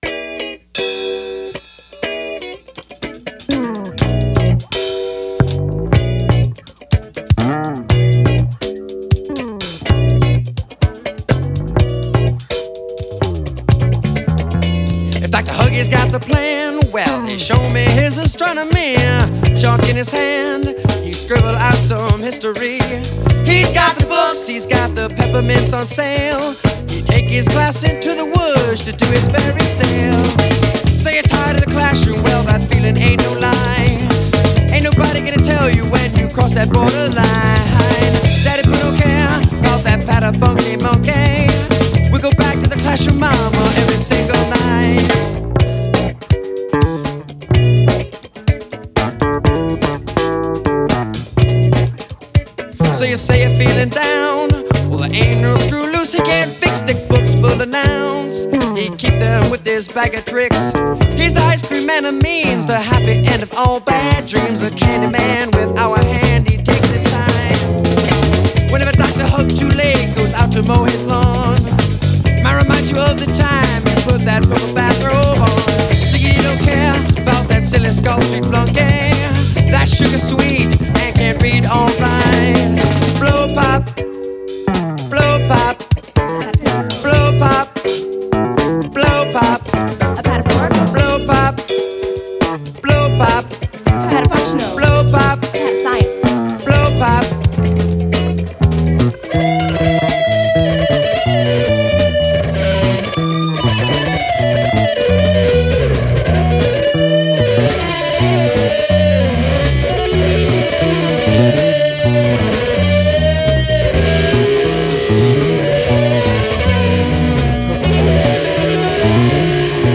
lead vocals
bass
drums
guitar